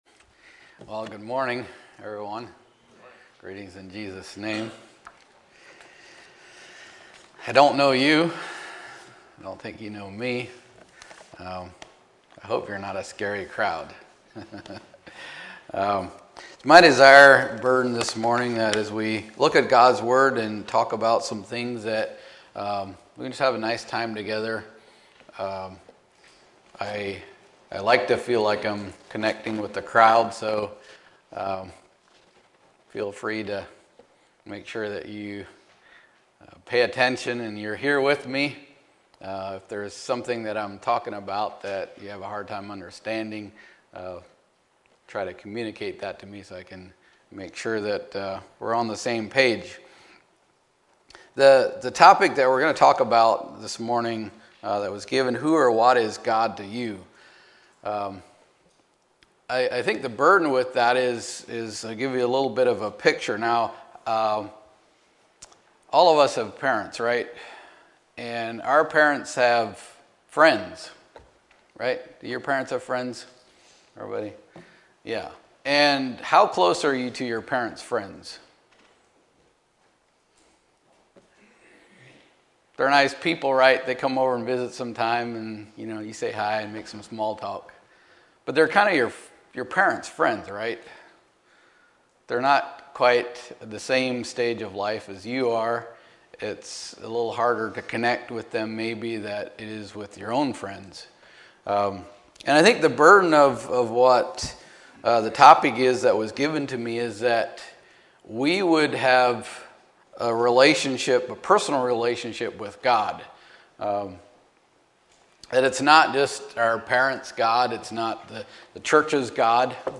2024 Youth Meetings , Youth Meeting Messages